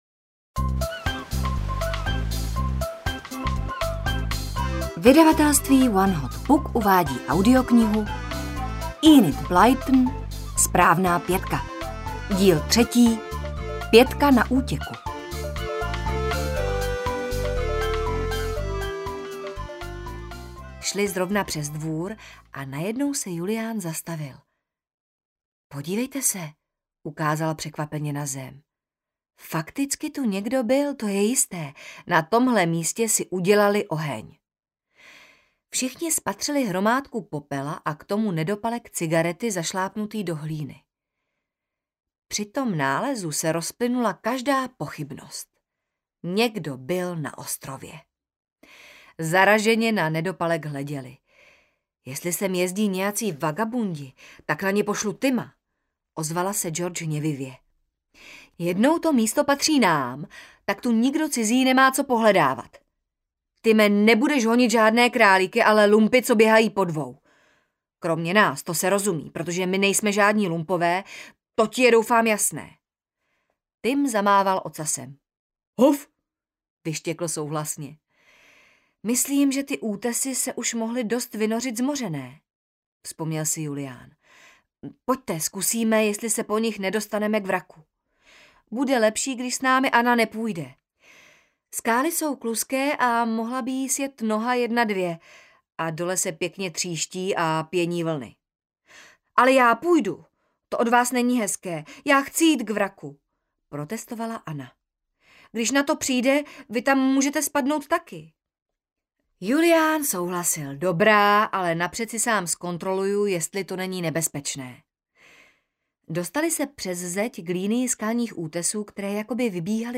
Ukázka z knihy
• InterpretMichaela Maurerová